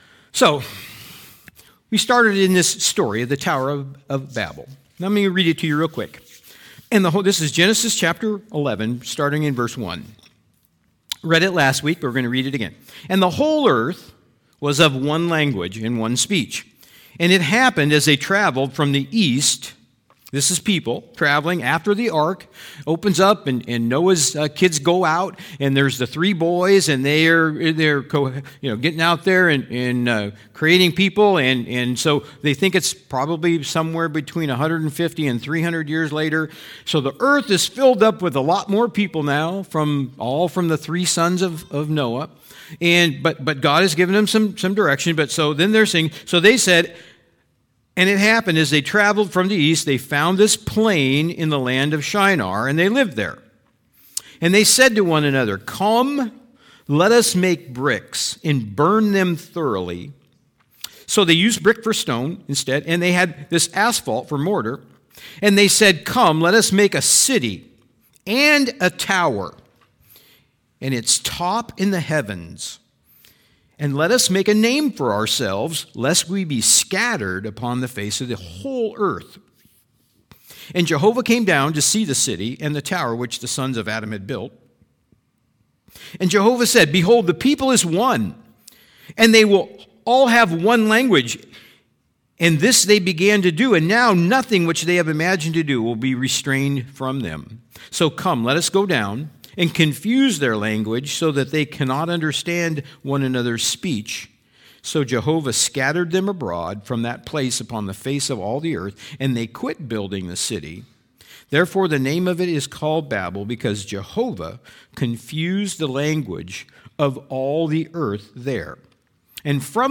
Sermons | Machias Community Church